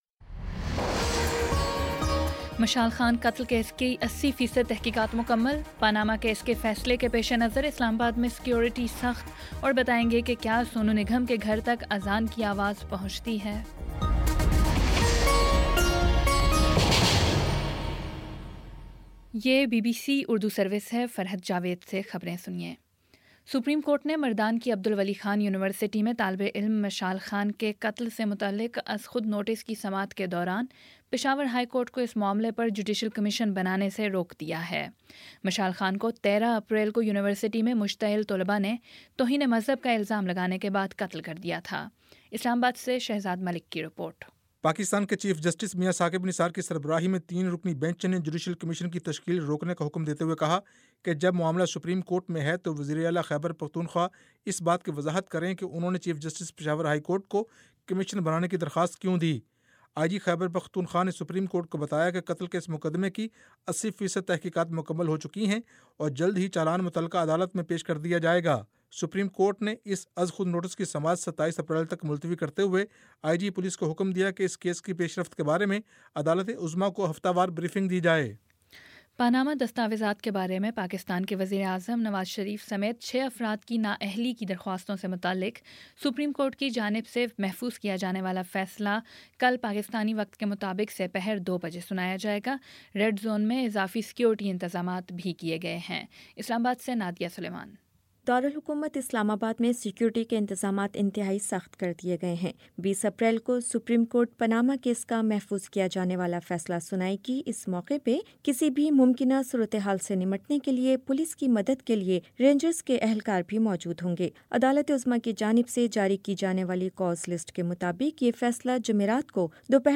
اپریل 19 : شام سات بجے کا نیوز بُلیٹن